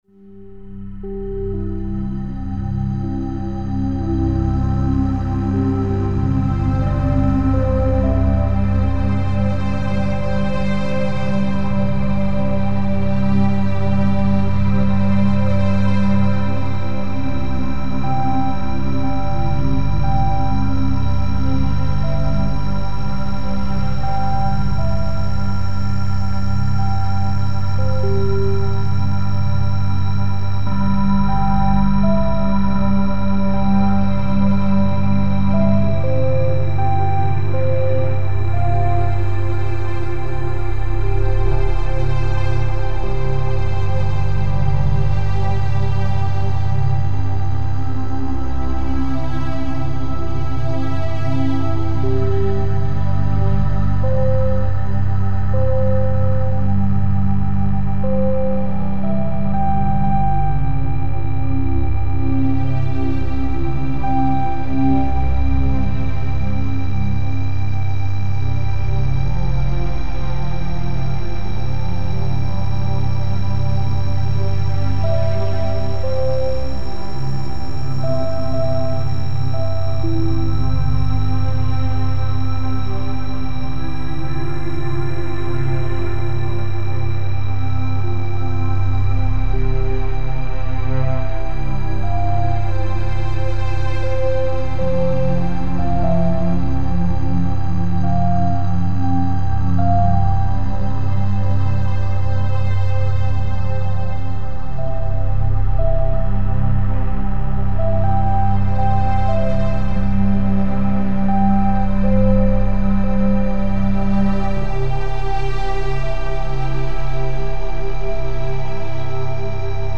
(November 11) — The First Instance was an audio recording (on my desktop) of audio from a first-generation iPad Air.
For the first hour of each mix, the switch from one instance to the next will be pretty precisely at twelve-minute intervals, with no cross-fading and just a short fade-out / fade-in to mark the transition.